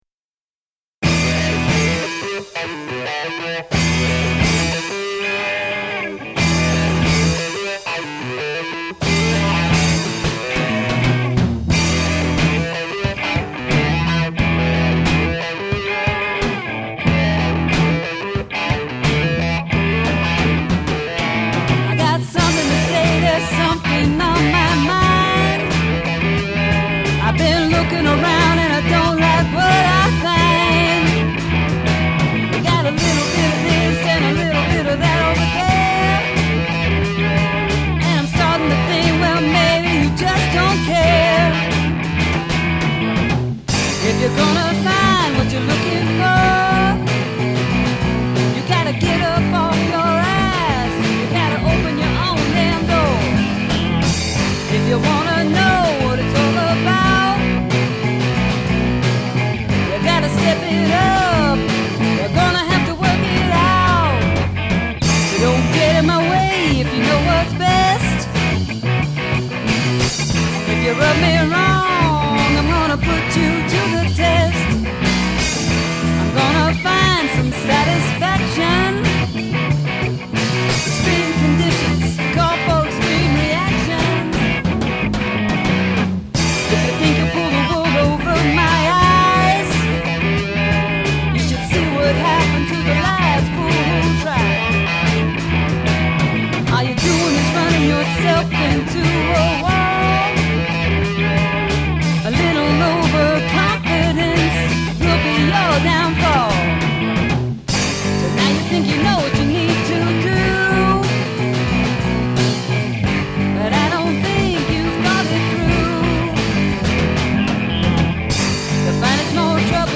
Guitar / Bass
Guitar / Drums
Vocals